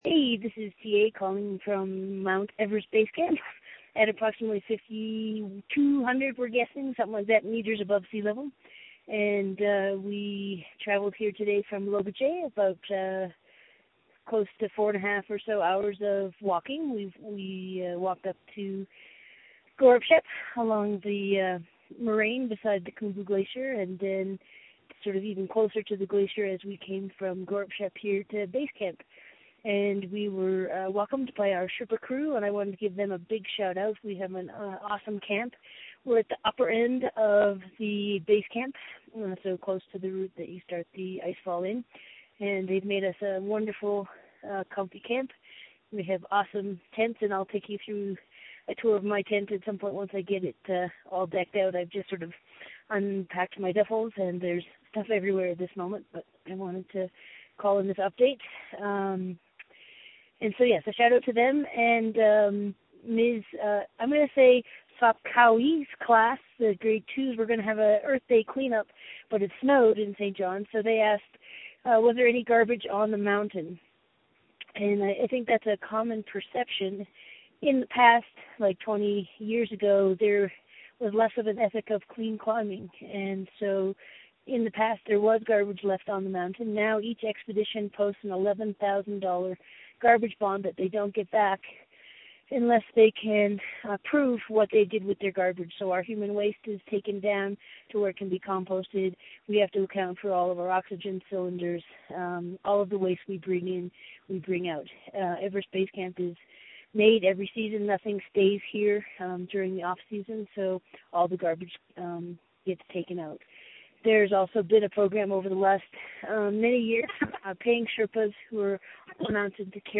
Audio Post Everest Base Camp – Everest 3.0 Day 13